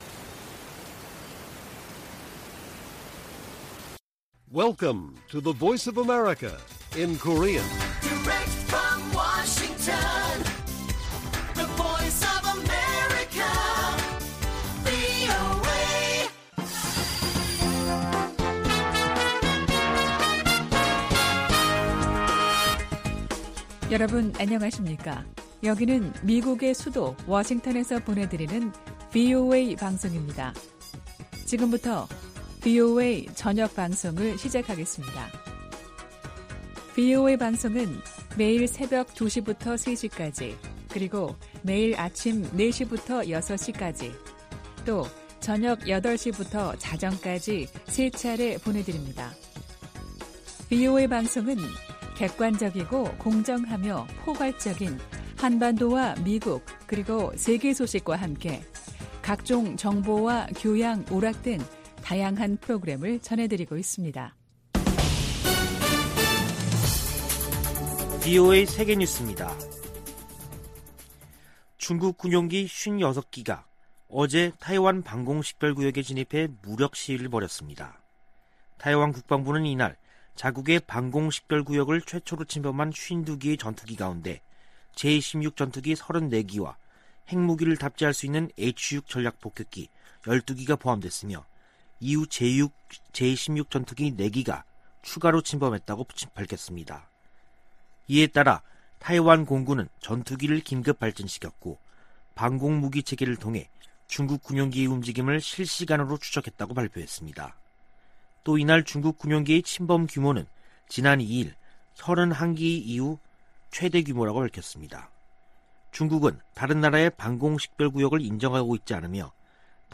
VOA 한국어 간판 뉴스 프로그램 '뉴스 투데이', 2021년 10월 5일 1부 방송입니다. 유엔 안보리 전문가패널이 북한의 제재 위반 사례를 담은 보고서를 공개했습니다. 미 국무부는 북한이 안보리 긴급회의 소집을 비난한 데 대해 유엔 대북제재의 완전한 이행 필요성을 강조했습니다. 문재인 한국 대통령은 남북한 체재경쟁이나 국력 비교는 의미 없어진 지 오래라며 협력 의지를 밝혔습니다.